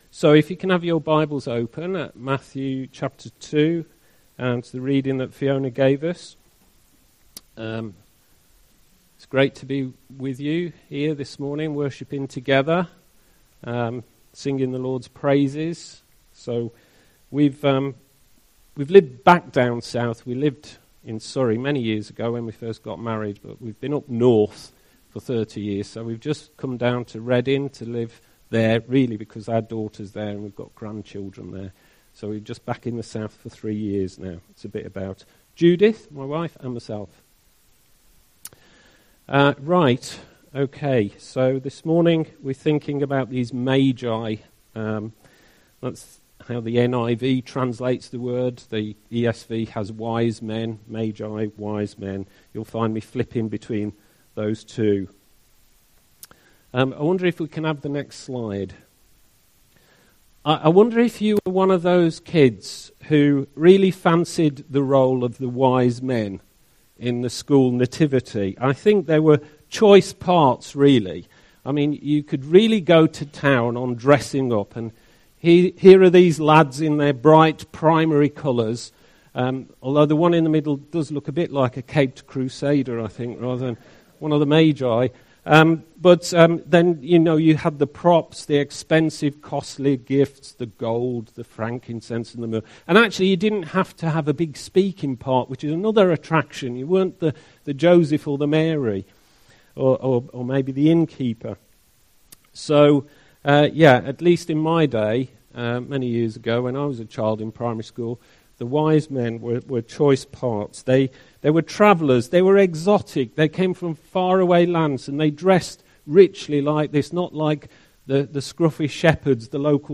The best way to listen and view the slides at the same time is to first play the sermon (click on the play button below), and then click on the "View associated slides" button which opens the slides in a separate window, while still playing the message.